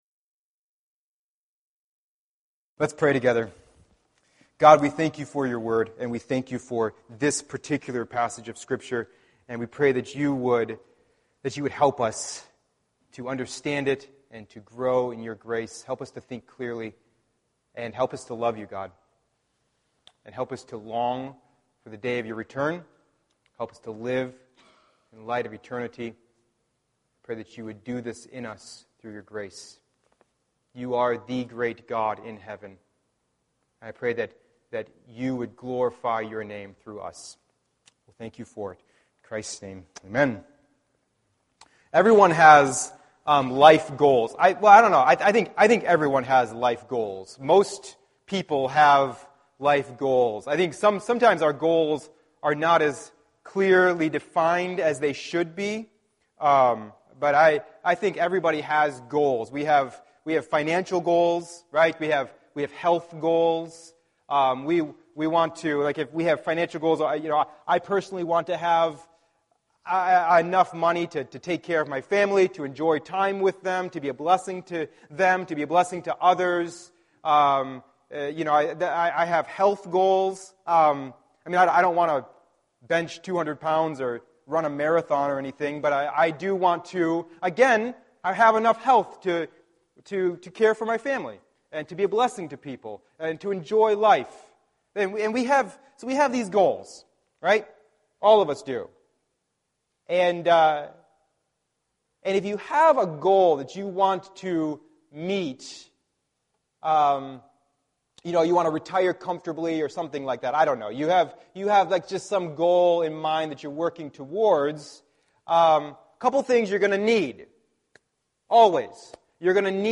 Sermons - First Baptist Church Warsaw